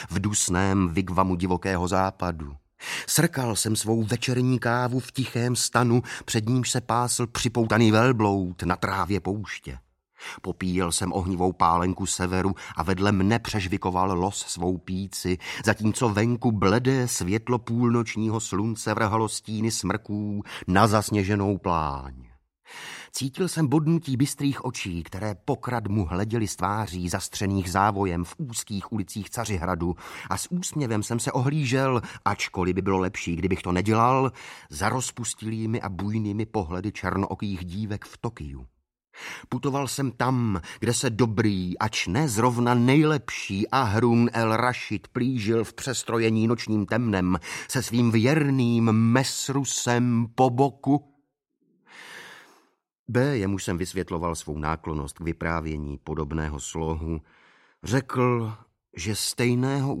Audiobook
Read: Igor Bareš